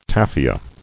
[taf·i·a]